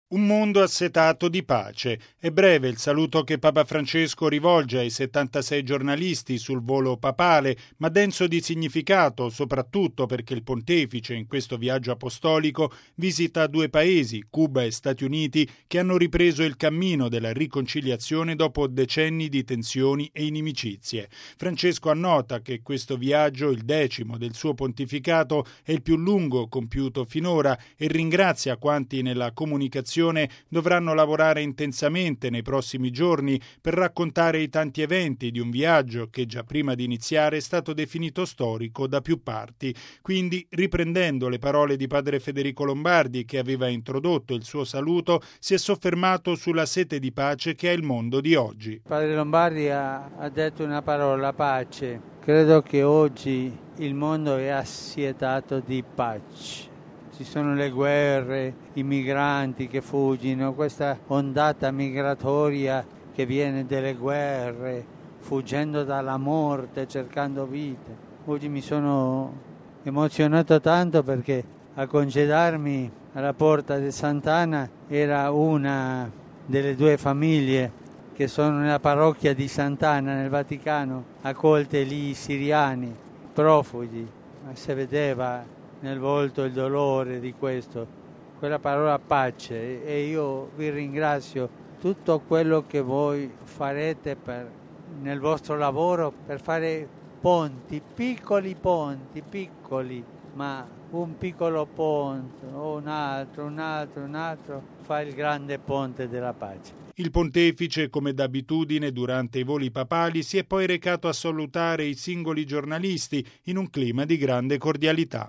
E’ quanto affermato da Papa Francesco sull’aereo che lo portava a Cuba, nel consueto saluto ai giornalisti sul volo papale.